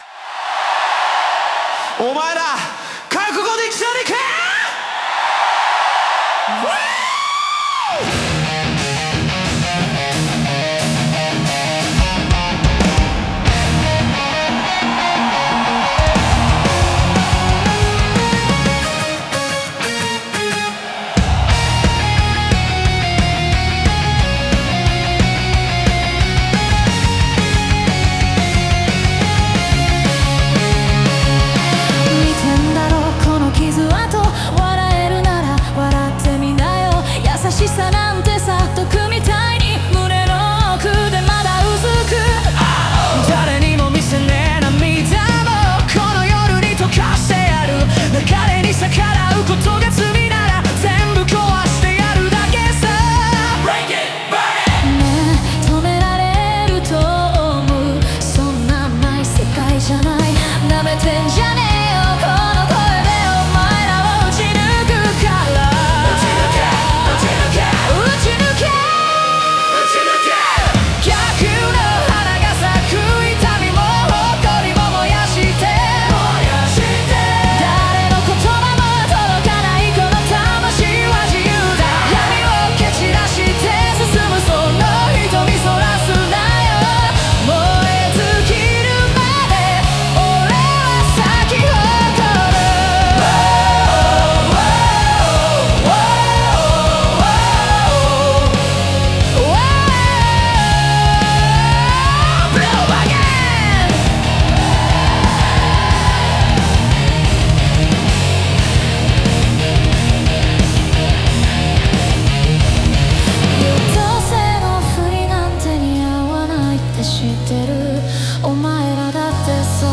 ライブの熱気や観客との一体感が、反逆と解放の物語をリアルに体現している。